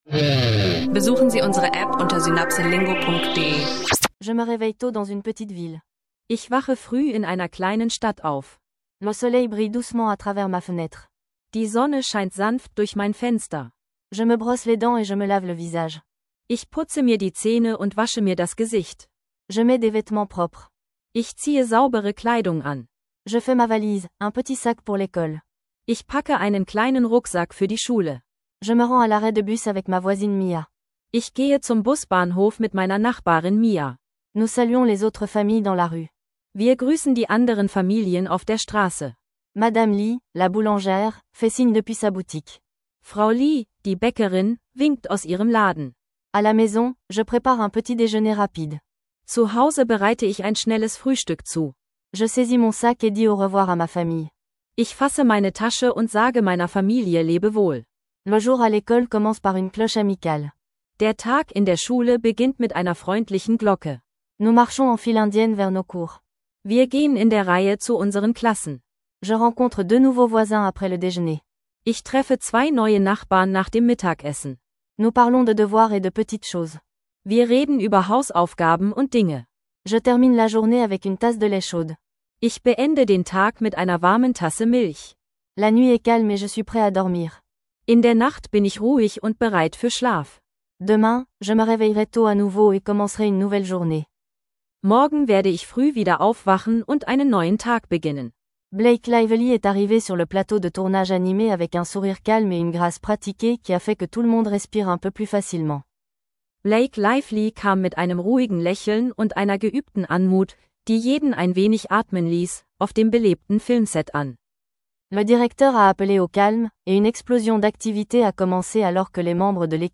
In dieser Folge üben wir Französisch im Alltag mit einfachen Dialogen über Morgentroutine, Schulweg und Nachbarschaft, ideal für Französisch lernen online und Französisch für Anfänger. Tauche ein in praxisnahe Sätze rund um Routine, Schule und Nachbarn.